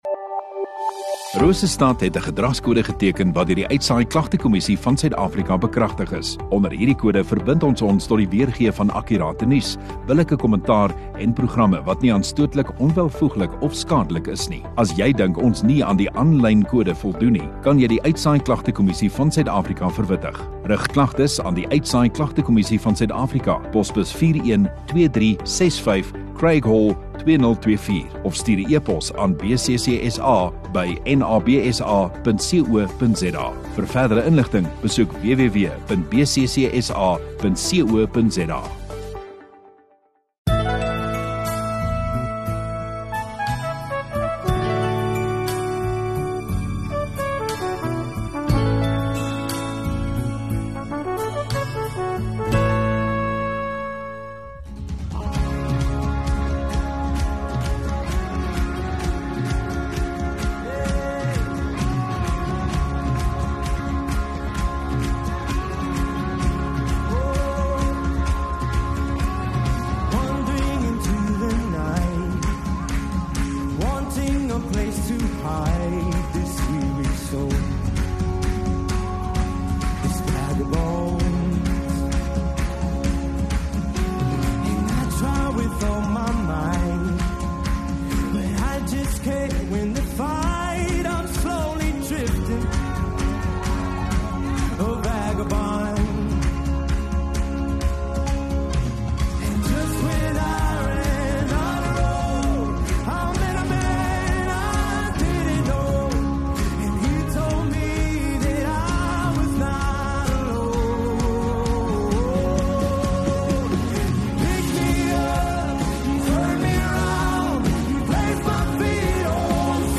Sondagoggend Erediens